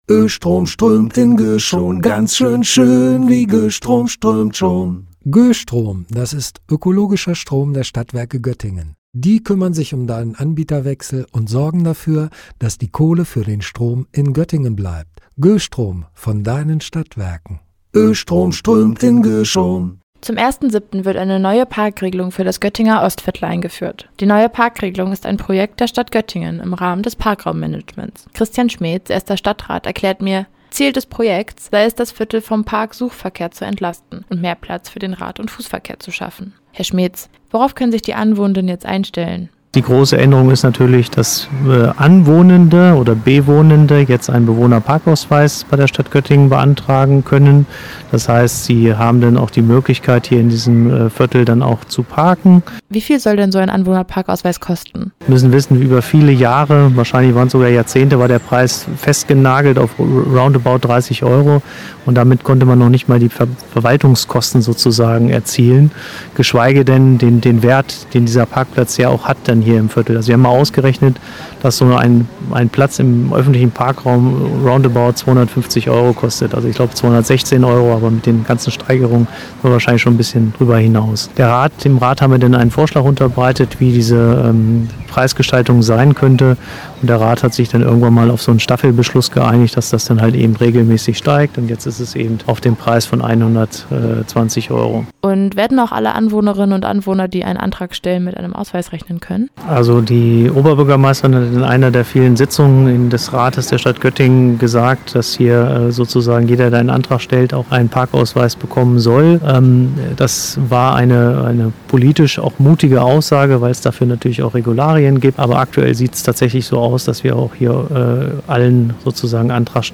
Sie hat darüber mit Christian Schmetz, dem ersten Stadtrat gesprochen.